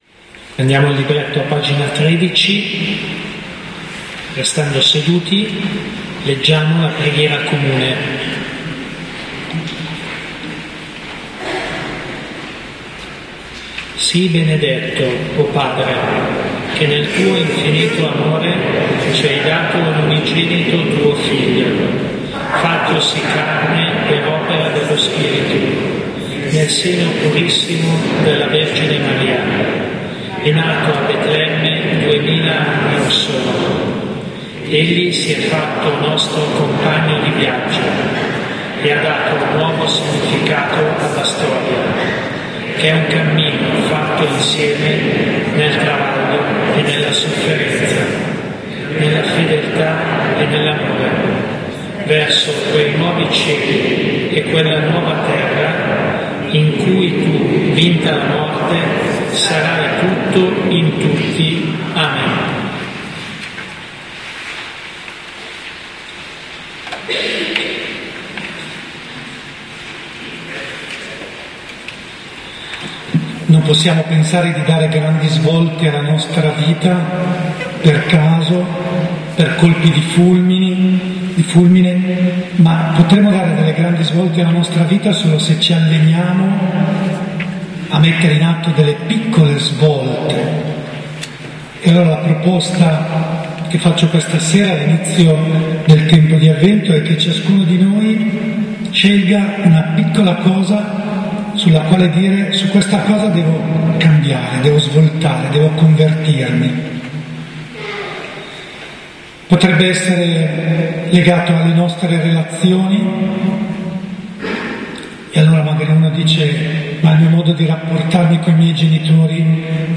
Zona V: Chiesa Santi Ambrogio e Sempliciano di Carate Brianza (MB)